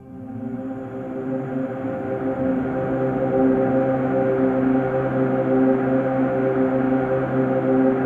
ATMOPAD09 -LR.wav